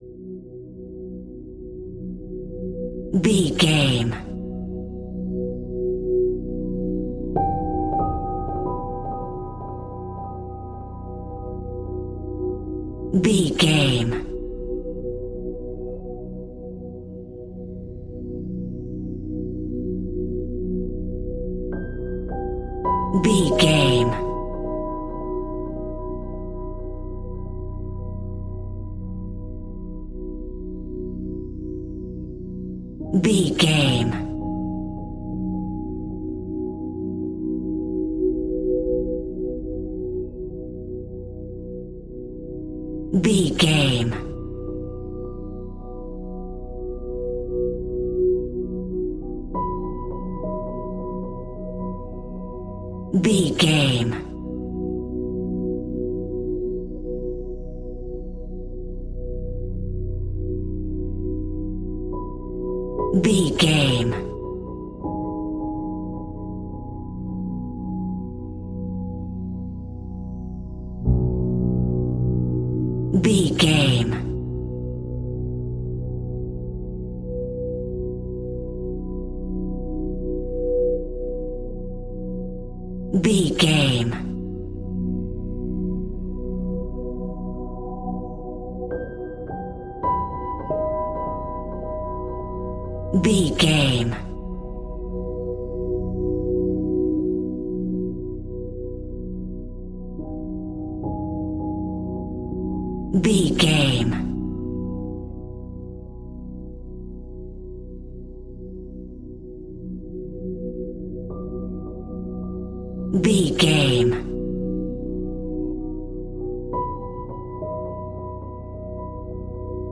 Scary Space Horror Music.
Aeolian/Minor
Slow
ominous
eerie
synthesiser
piano
strings
Horror Pads